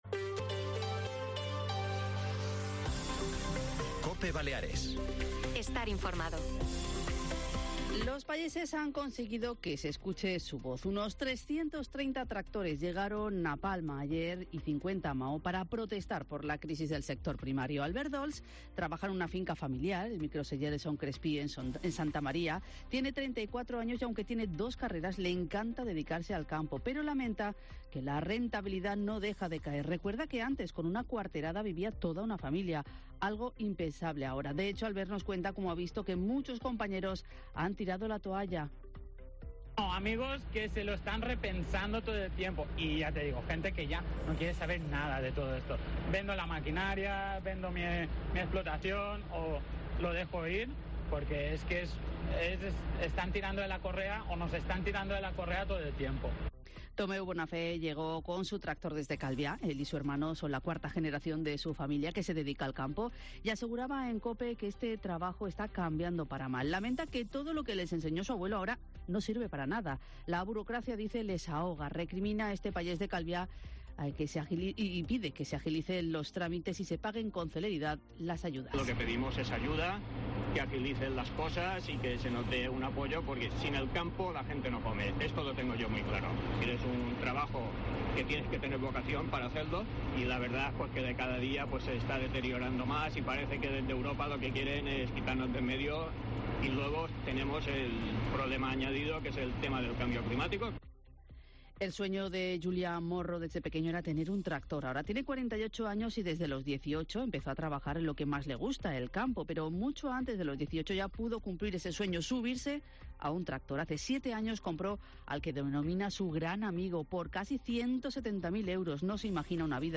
desde sus tractores en medio de la tractorada